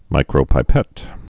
(mīkrō-pī-pĕt)